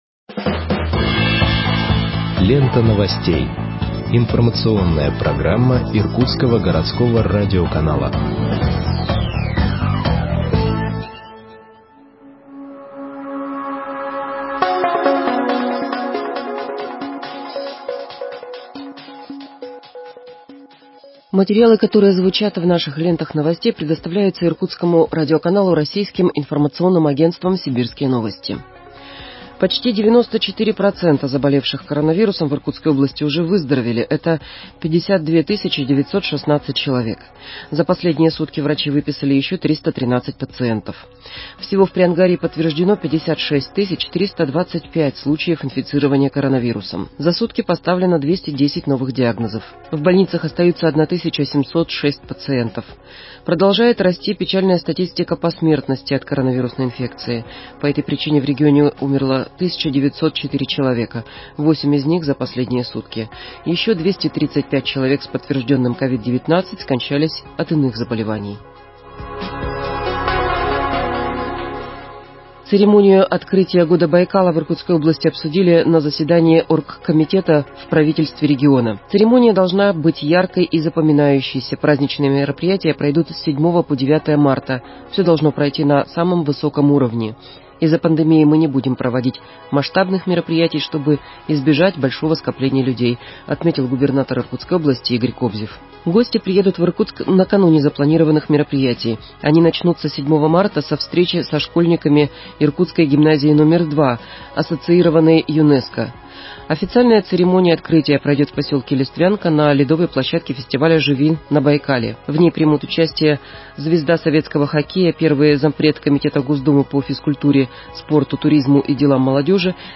Выпуск новостей в подкастах газеты Иркутск от 02.03.2021 № 2